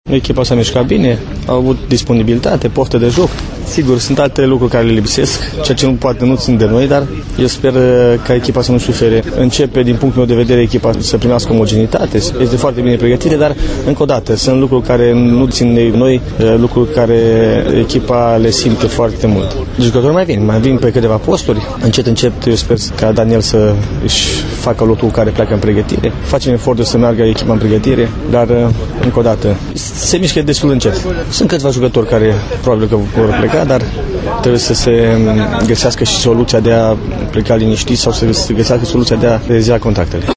Primarul Reşiţei, Mihai Stepanescu, a asistat la meci, iar în declaraţia făcută la final